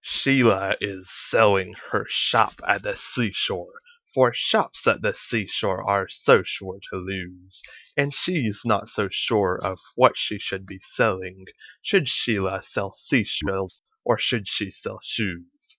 Practice (first part, not enough diaphragm)